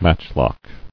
[match·lock]